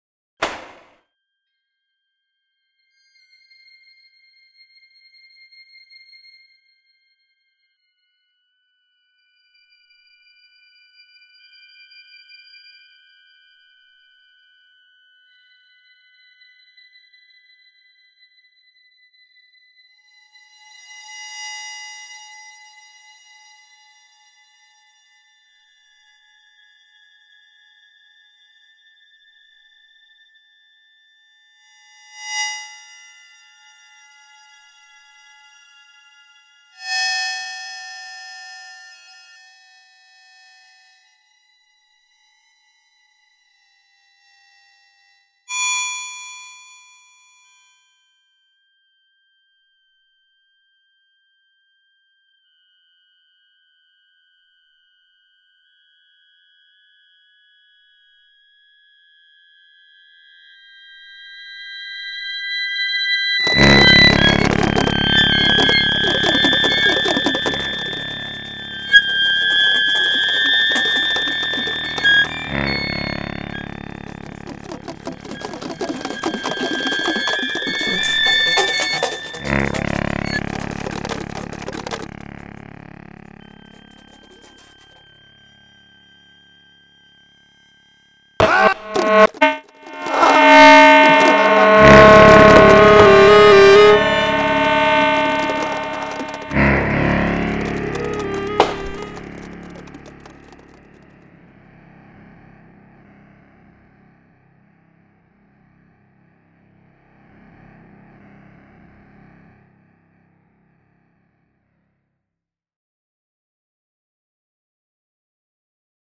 live electronics
Electronics 1